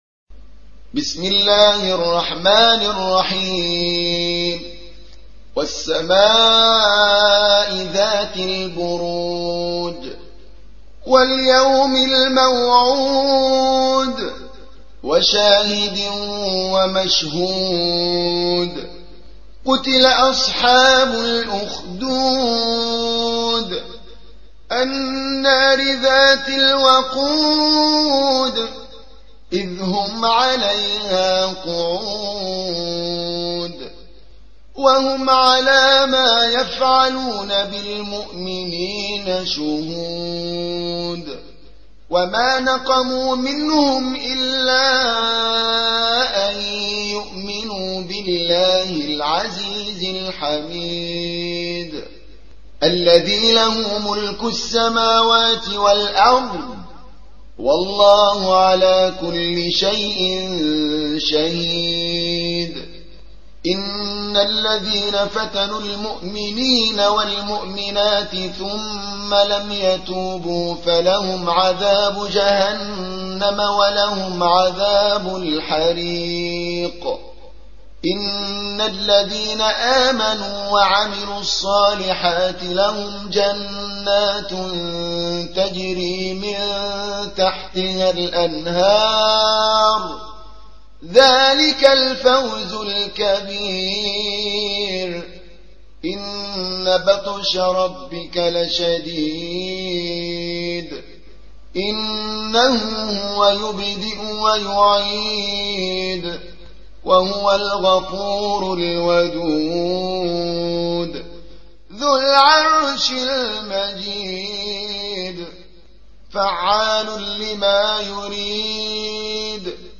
85. سورة البروج / القارئ